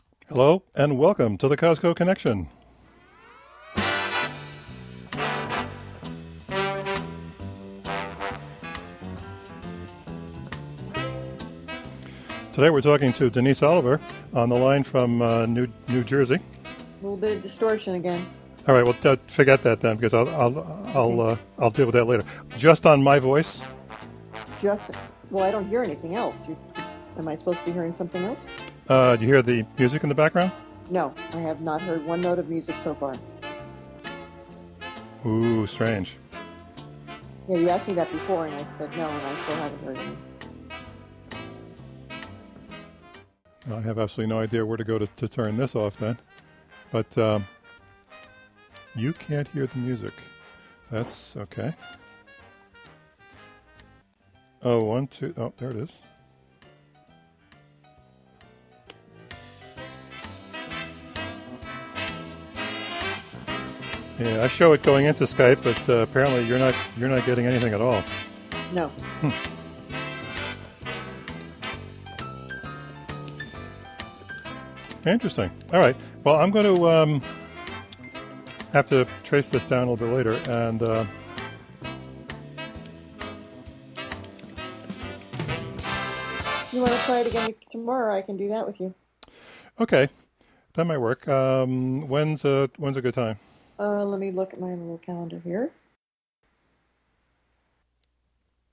This is a work in progress sound file. I do my show until I get stuck.